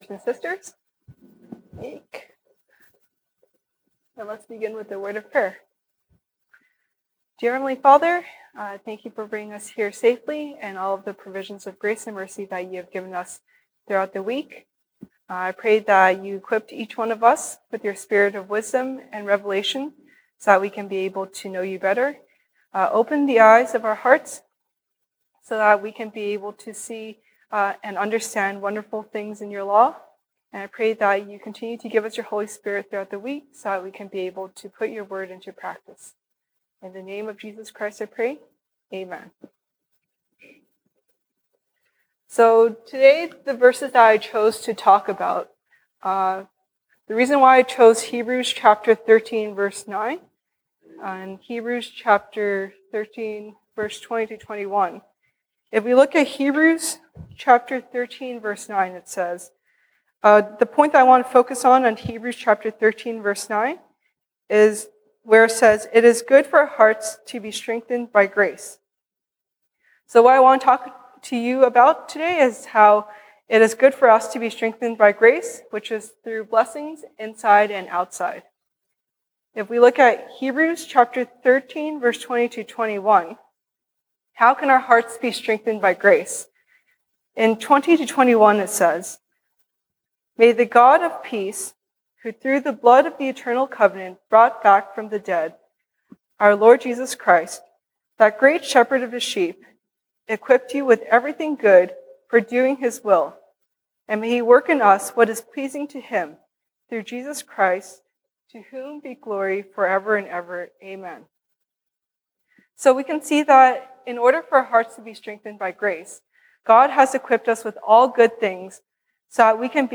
西堂證道 (英語) Sunday Service English: Blessings Inside and Outside